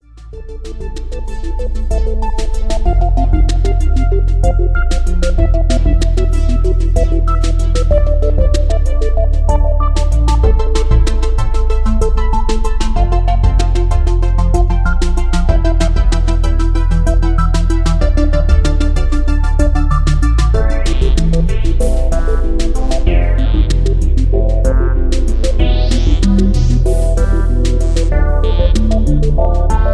Dramatic Electro Ambient